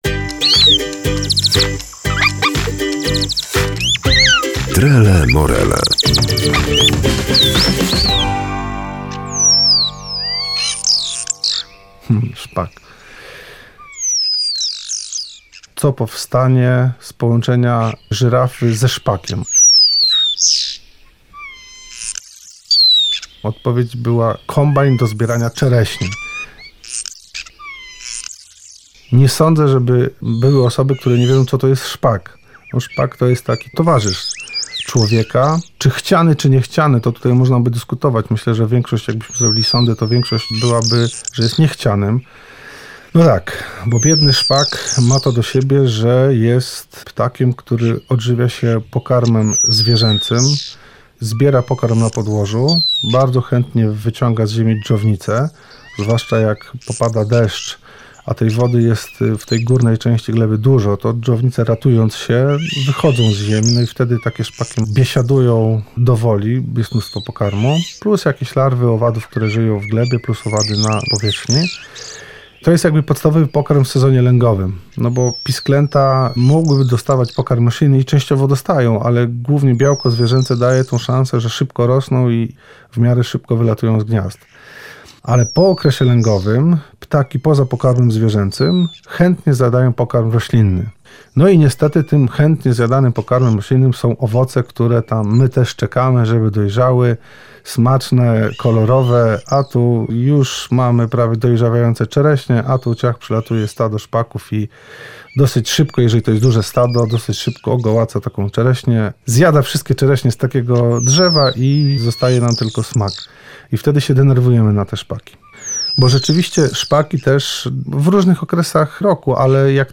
Szpak - mistrz jedzenia czereśni? O tym wyjątkowym ptaku opowiada ornitolog